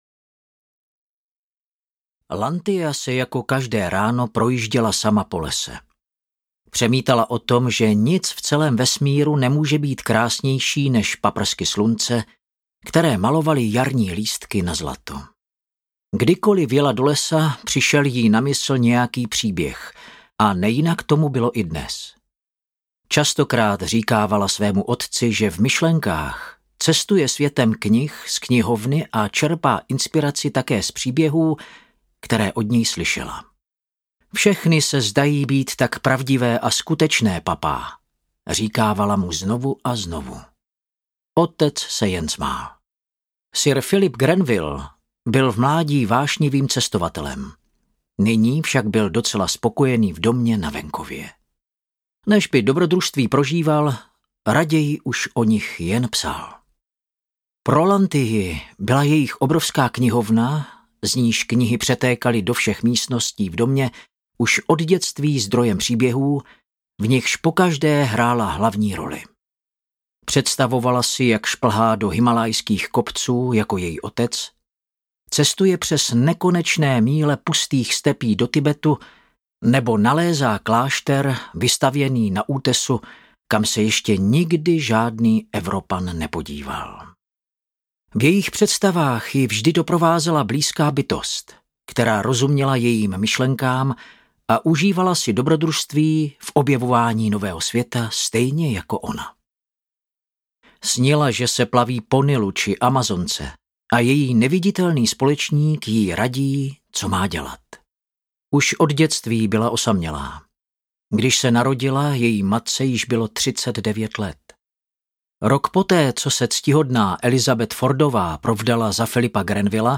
Milenci v Londýně audiokniha
Ukázka z knihy